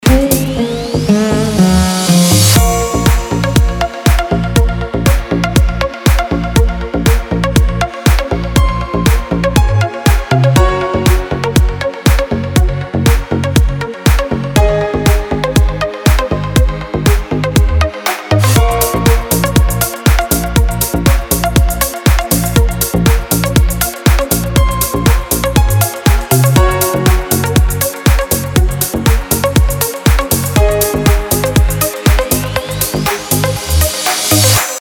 deep house
мелодичные
Electronic
без слов
восточные
Стиль: deep house.